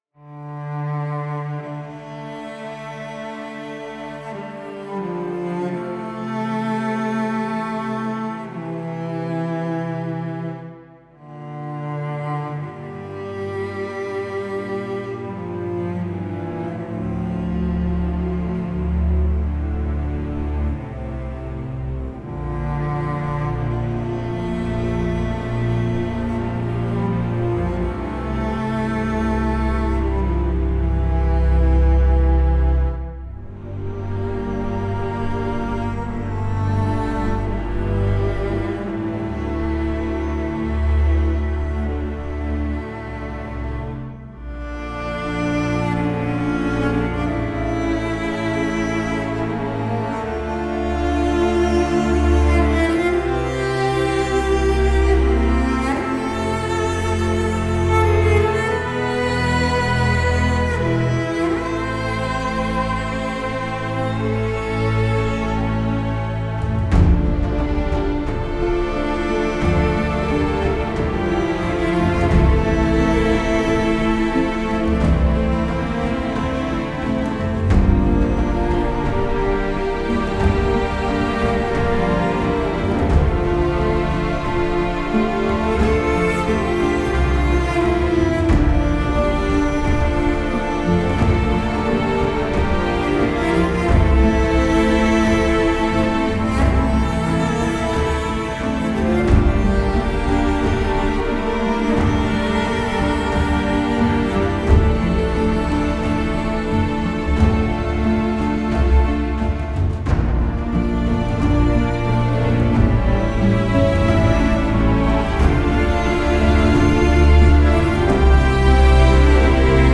orchestral strings library
The result is a string library that is easy to use, composer-friendly, with unparalleled recording quality.